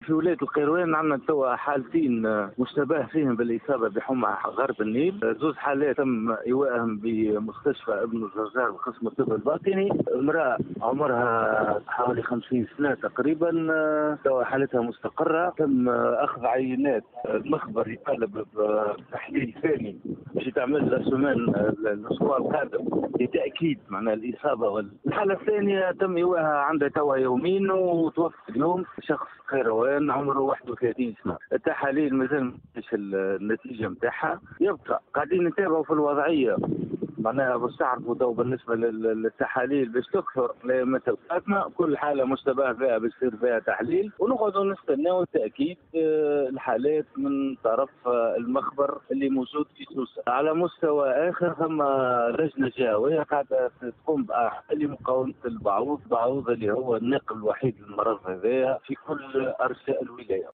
وأكد المدير الجهوي للصحة بالقيروان سامي الرقيق في تصريح